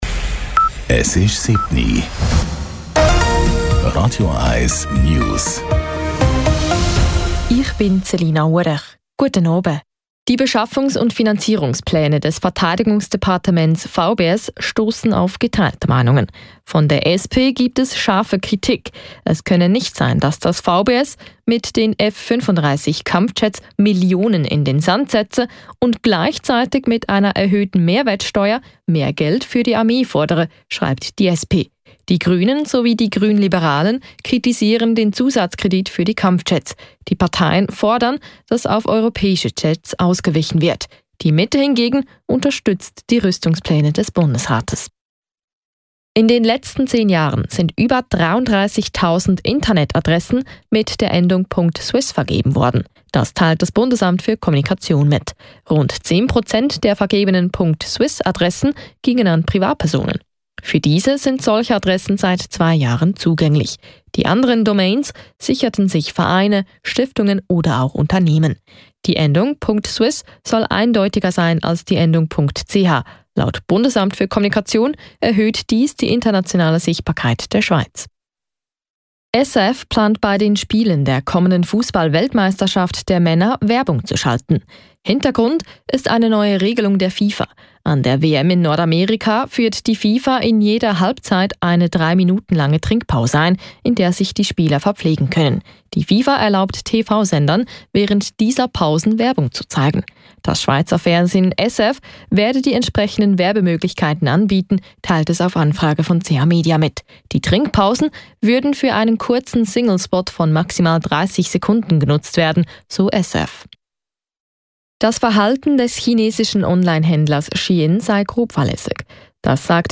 Die aktuellsten News von Radio 1 - kompakt, aktuell und auf den Punkt gebracht.
Die letzten News von Radio 1